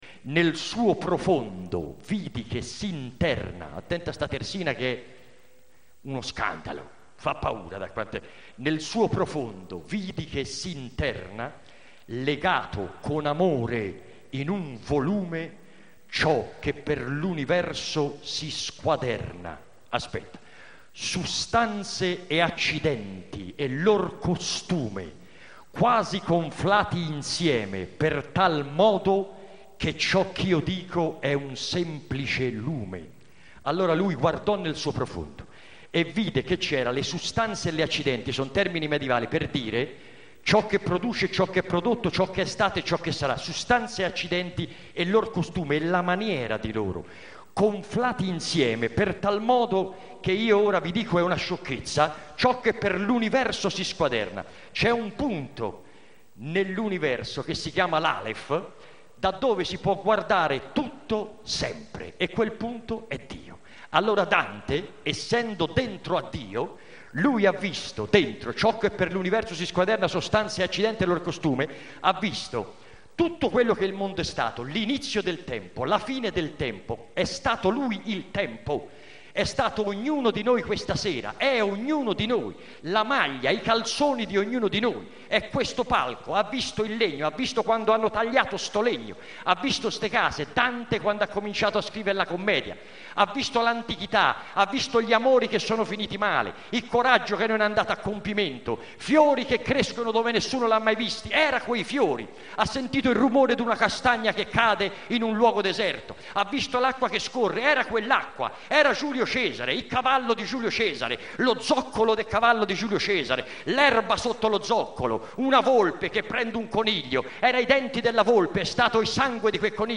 Tutto Dante, dallo spettacolo dedicato al XXXIII Canto del Paradiso.